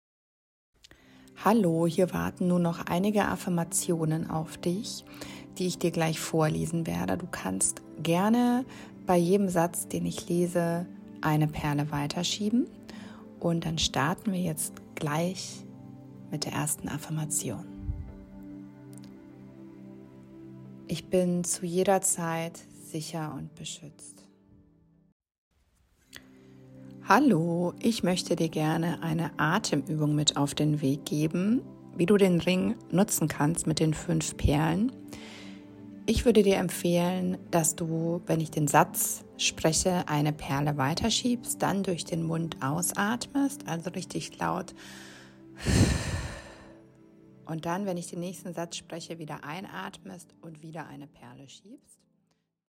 • Sanfte Affirmationen – wohltuende Sätze, die dein Nervensystem unterstützen und innere Ruhe stärken.
• Geführte Atemübungen – kurze Übungen, die dich in Stressmomenten sofort grounded und entspannter machen.
Entspannung mit den Audio Affirmationen und Übungen des Anxiety Fidget Rings.
Anxiety-Fidget-Ring-Audio-Uebungen-Affirmationen-Previw.mp3